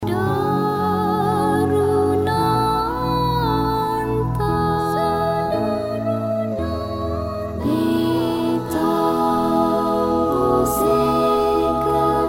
Malaysian Buddhist singing group
English Buddhist songs